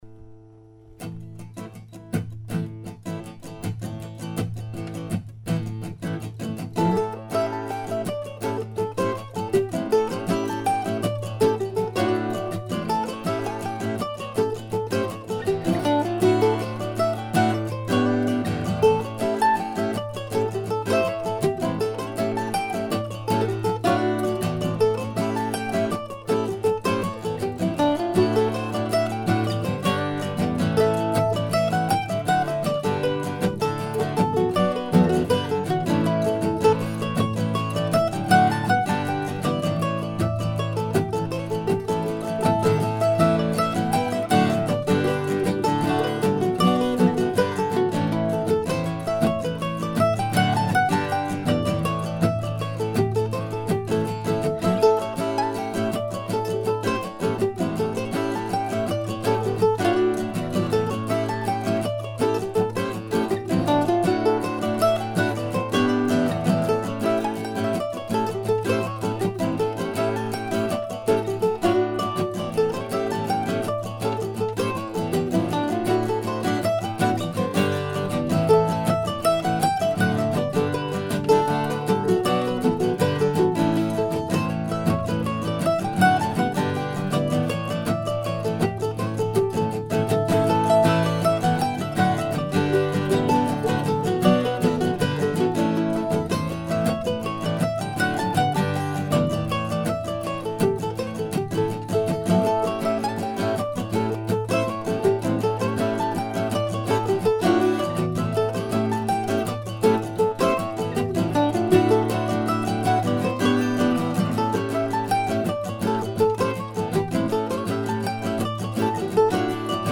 The tune itself was mostly written on a fine old Gibson oval hole mandolin in Carrboro, NC the night before we drove to Swan Quarter to catch the ferry to Ocracoke. Recorded here in Decorah on a very warm night last week.